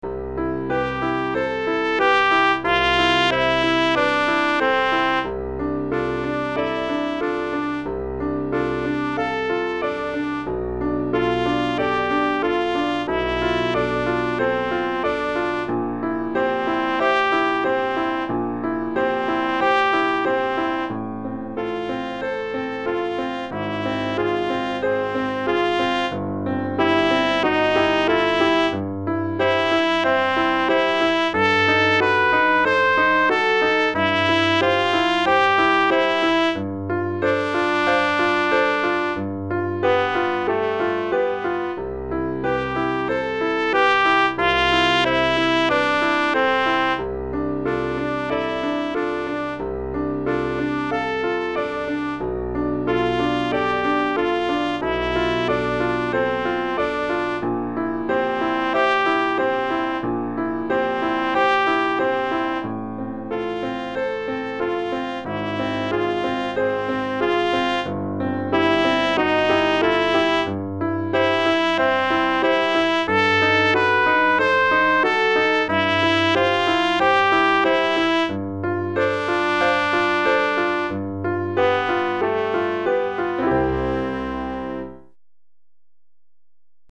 Répertoire pour Trompette ou cornet